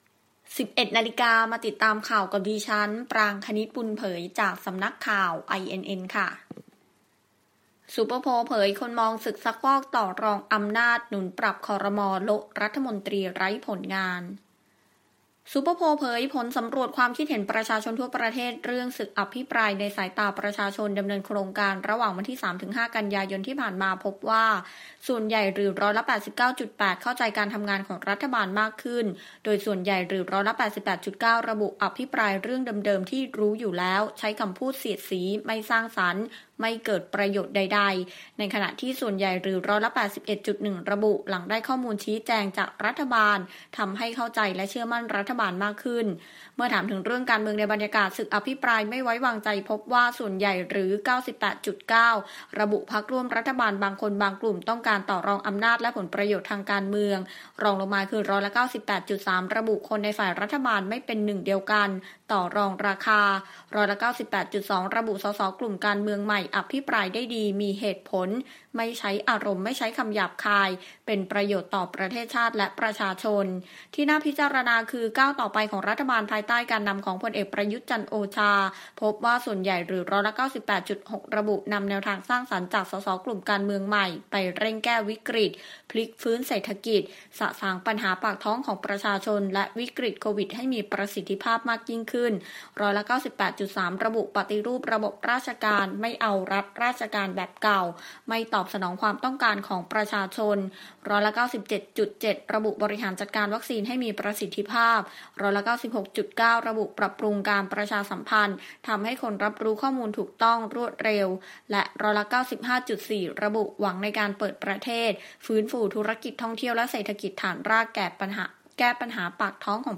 ข่าวต้นชั่วโมง 11.00 น.